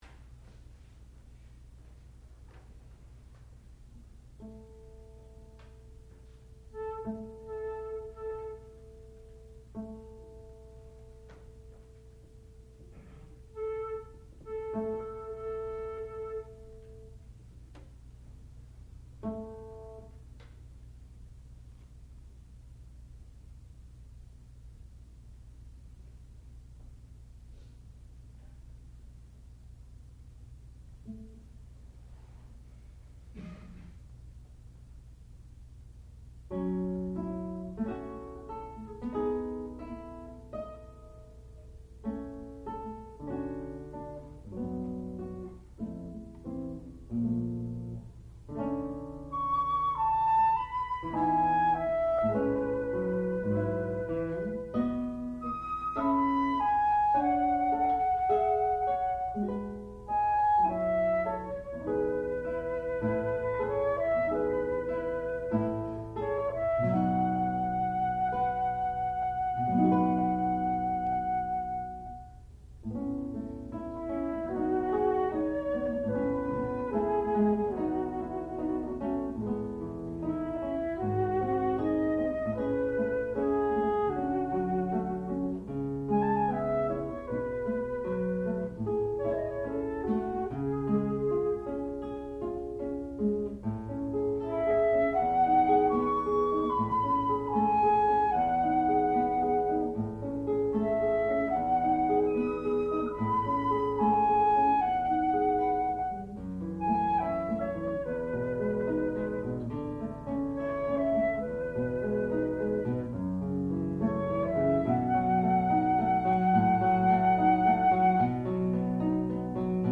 Four Excursions for Guitar and Flute (1971)
classical music
Advanced Recital